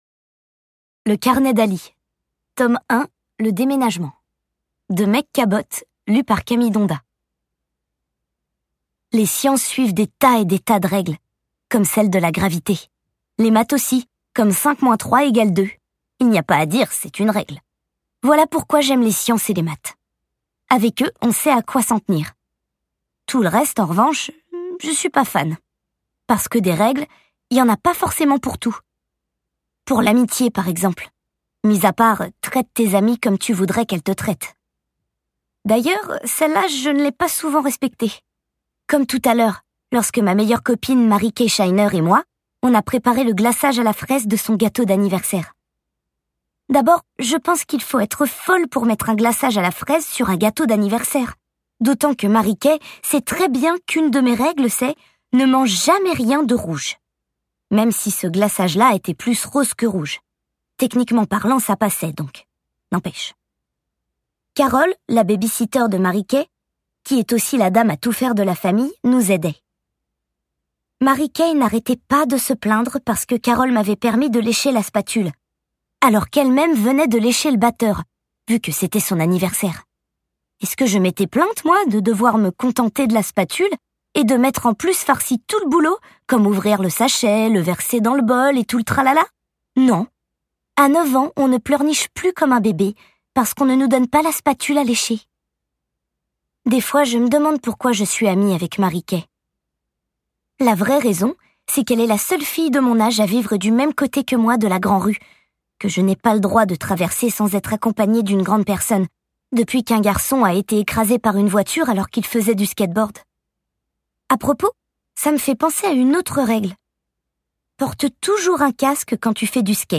Roman jeunesse
Elle incarne ici à merveille une petite fille d’une dizaine d’année. J’avais réellement l’impression d’écouter une enfant me racontait ses aventures et ses bêtises!